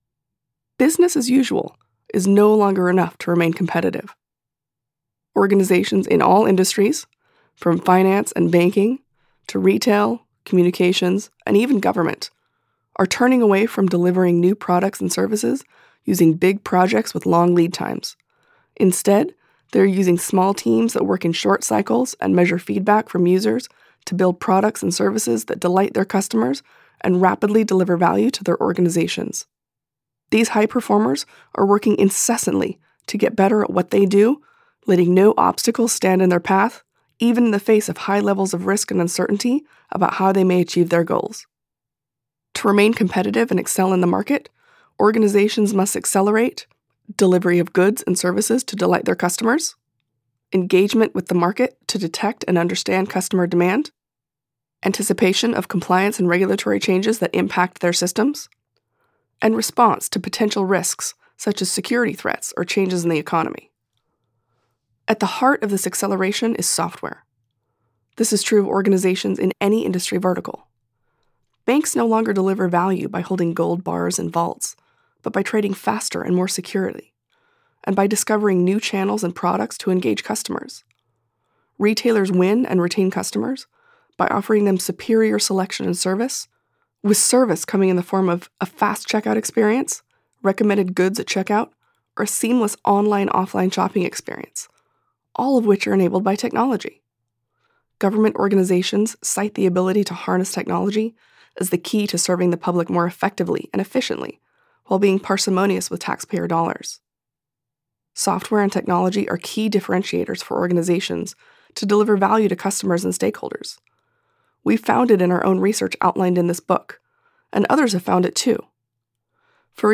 Accelerate - Audiobook Excerpt